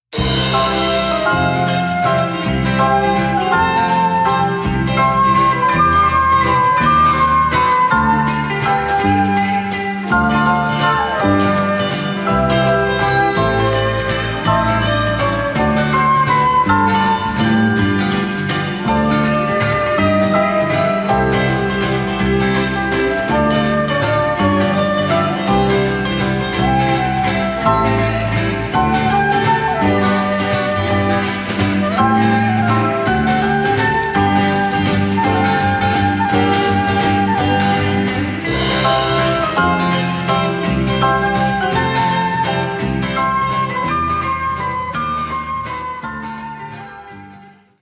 harmonic medium instr.